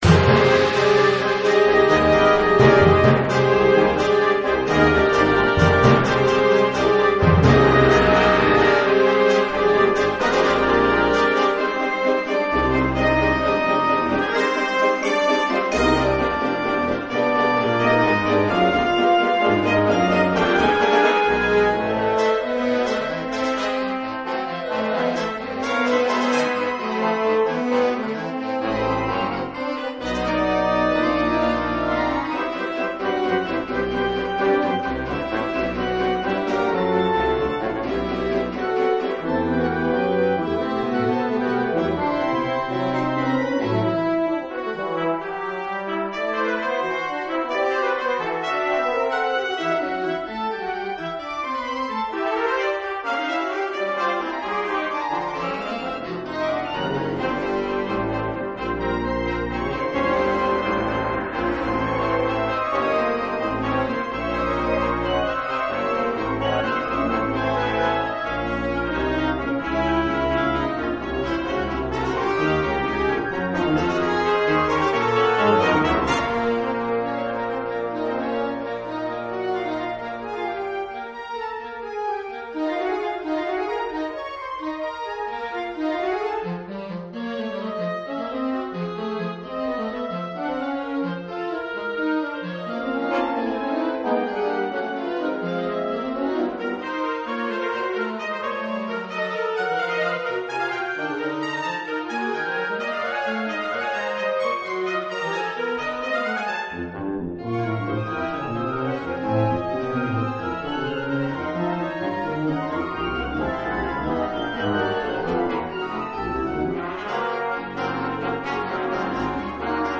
Allegro Deciso , une pièce pour orchestre d’harmonie
C’est une œuvre à  l’harmonie plus ambitieuse que la précédente sans que l’accès en soit plus difficile pour le commun des mélomanes.